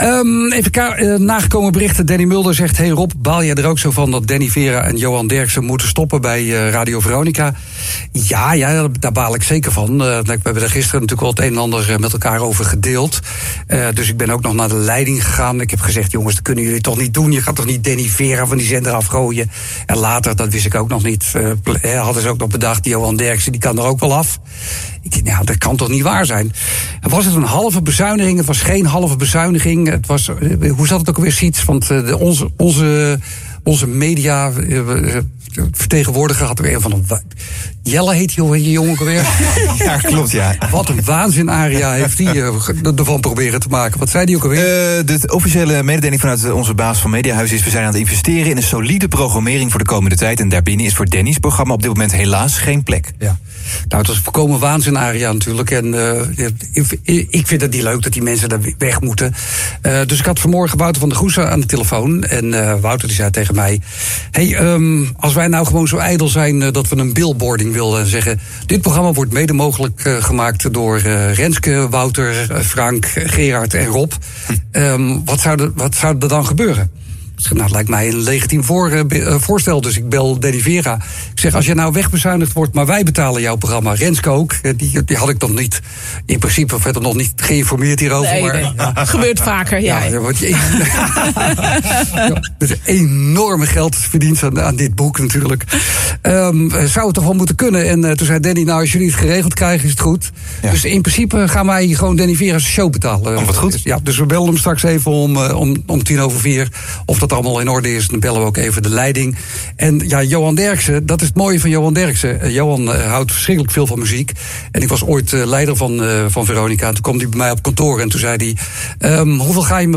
Rob Stenders is niet blij met de beslissing van de zenderleiding van Radio Veronica om de programma’s van Danny Vera en Johan Derksen te schrappen. “Ja daar baal ik van”, zo vertelde de deejay tijdens zijn programma.